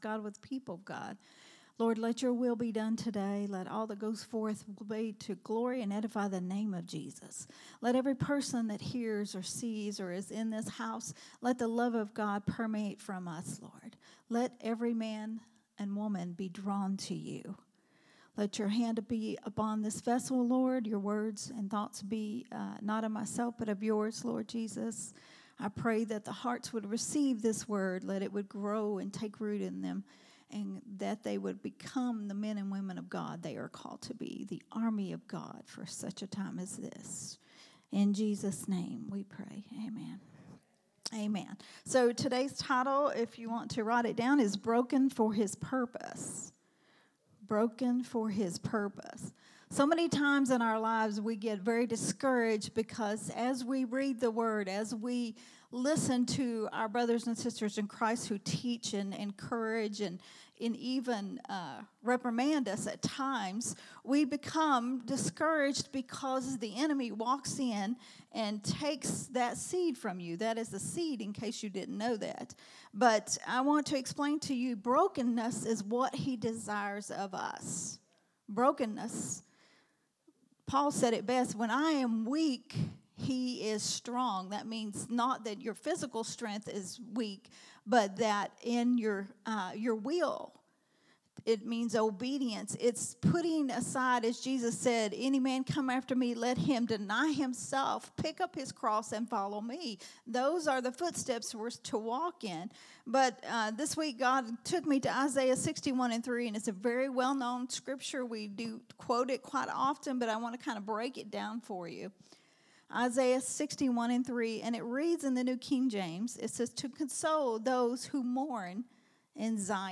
a Sunday Morning Risen Life teaching